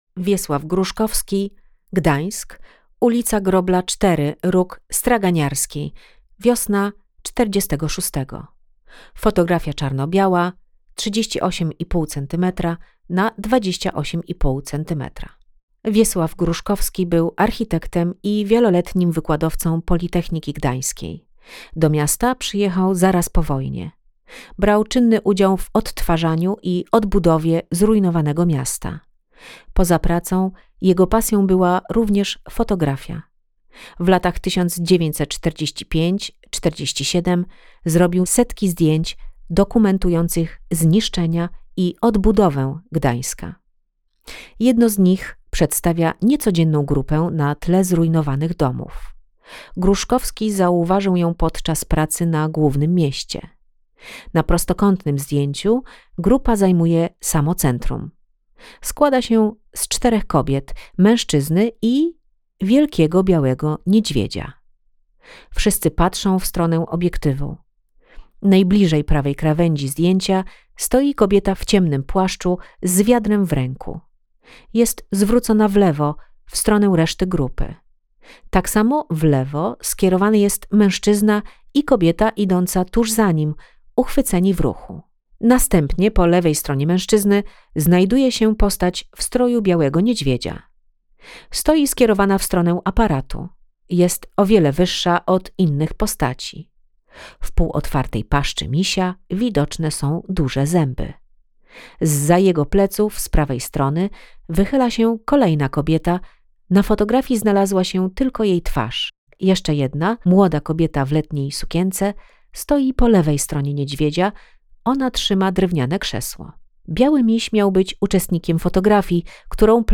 Audiodeskrypcja fotografii Wiesława Gruszkowskiego Uliczny fotograf. ul.